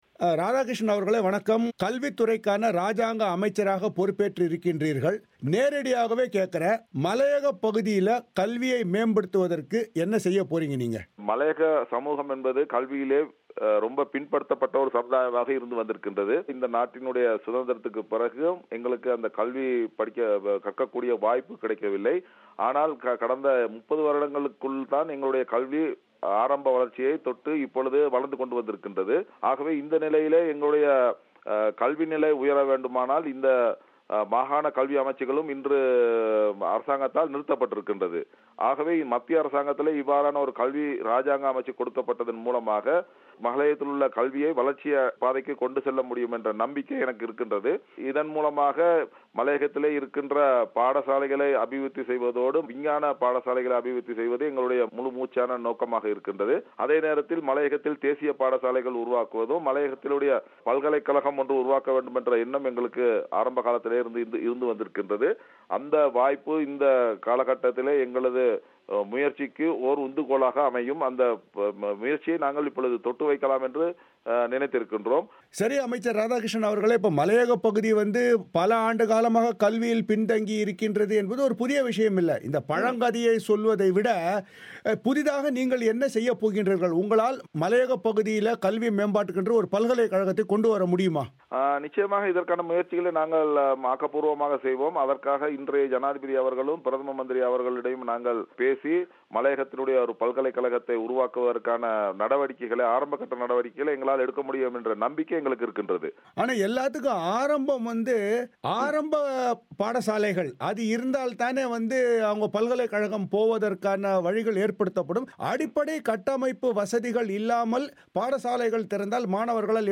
இலங்கையின் மலையகப் பகுதியில் கல்வி வளர்ச்சியை முன்னேற்ற புதிய ஜனாதிபதி மைத்திரிபால சிறிசேன உறுதியளித்துள்ளதாக கல்வித்துறைக்கான ராஜாங்க அமைச்சர் ராதாகிருஷ்ணன் கூறுகிறார். கடந்த பல தசாப்த்தங்களாக மலையகப் பகுதி கல்வித்துறையில் மிகவும் பின் தங்கியுள்ளது என்பதை புதிய ஜனாதிபதியும், பிரதமர் ரணில் விக்ரமசிங்கவும் உணர்ந்துள்ளனர் என அவர் பிபிசி தமிழோசையிடம் தெரிவித்தார்.